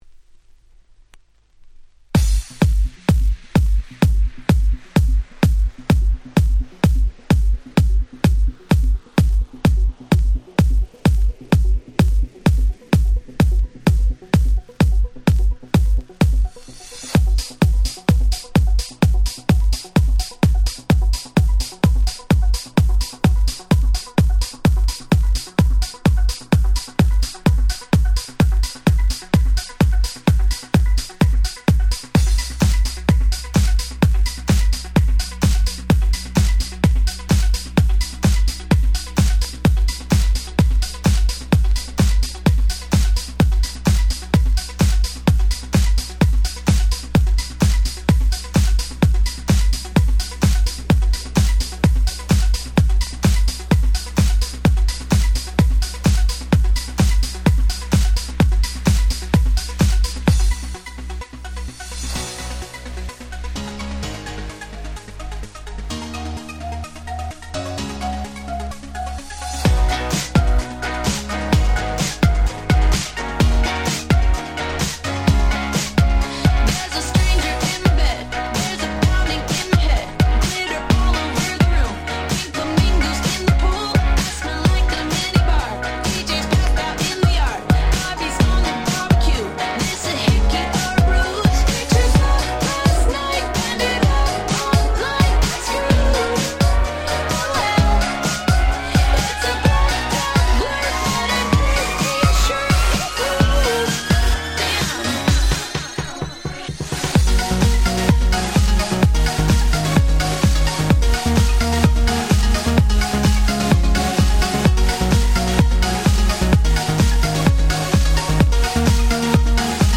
11' Super Hit R&B / Pops !!